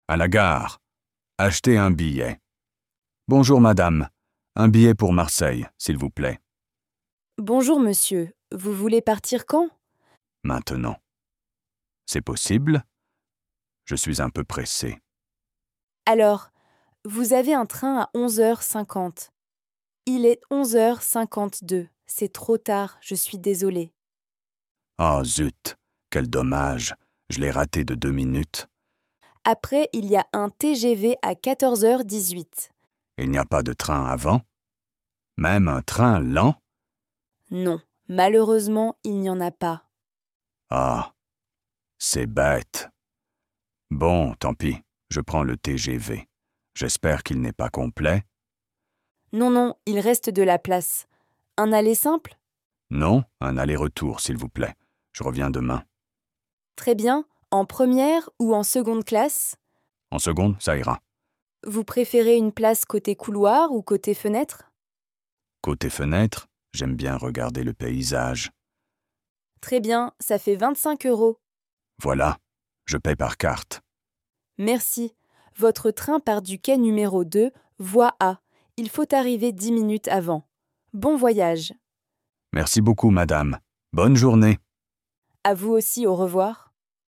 Dialogue FLE A2- À la gare – Acheter un billet